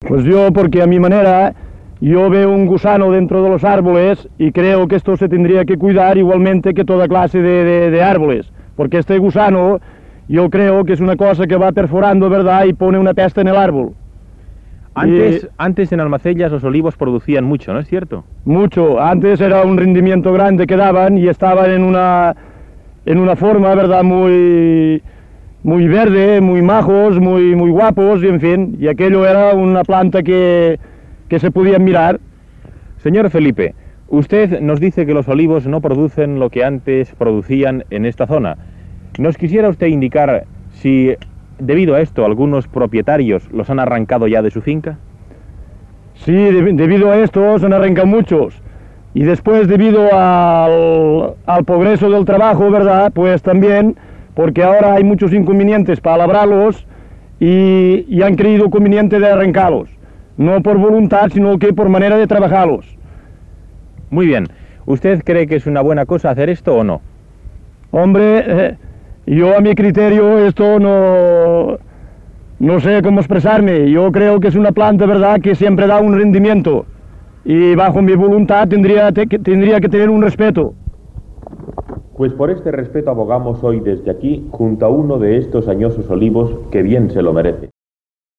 Entrevista a un pagès sobre les oliveres i la baixa producció
Informatiu